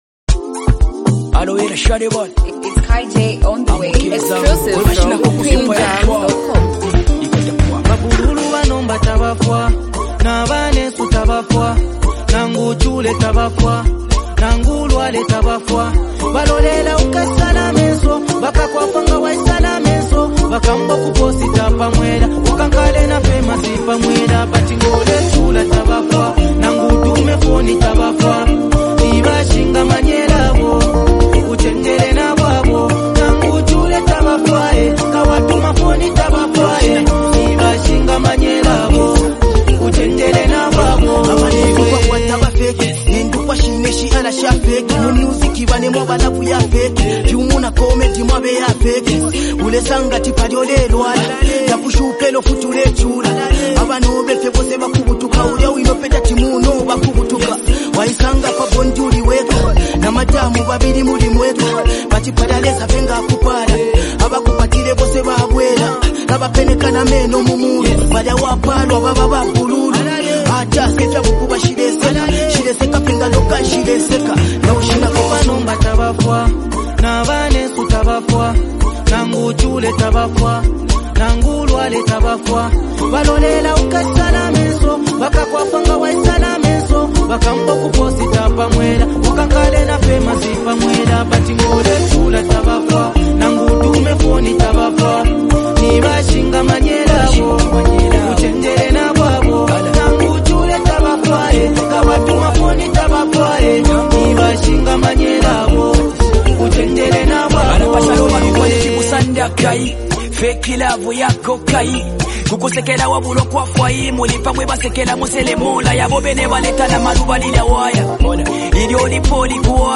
catchy melodic style and relatable delivery